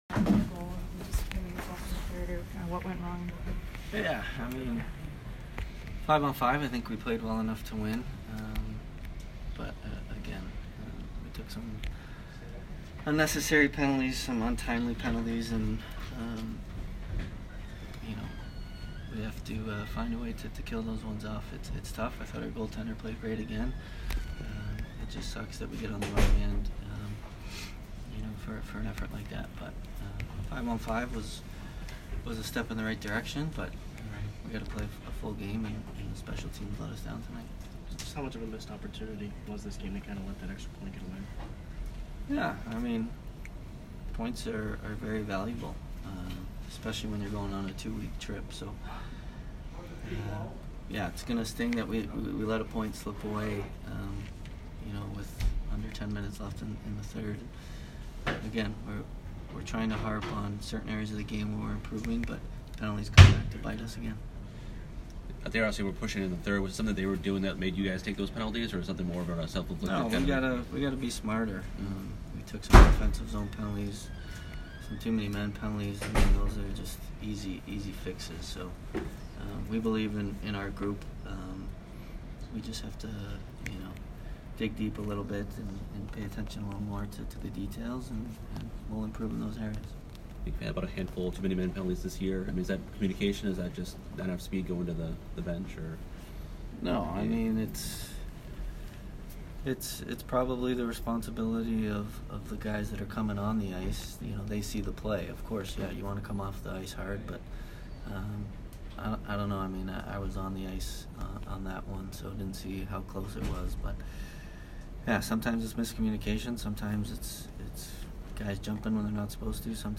Steven Stamkos post-game 10/26